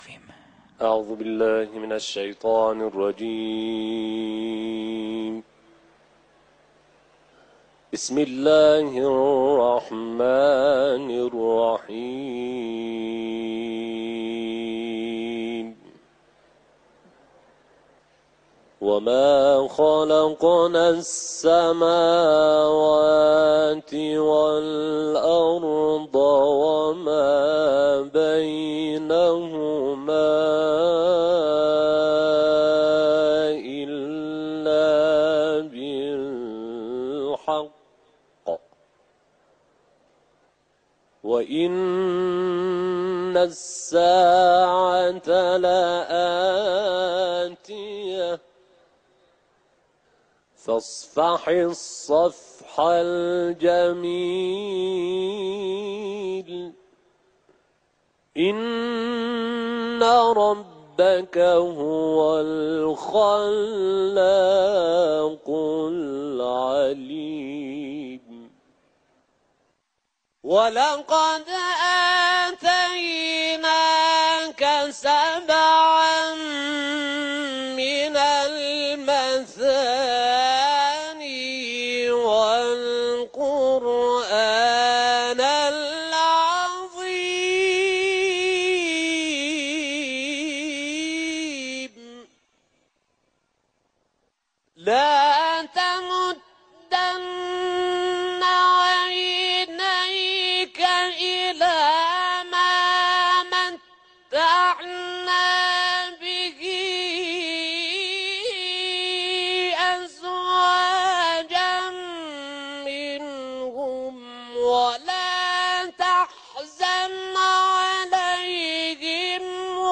تلاوت قرآن
حرم مطهر رضوی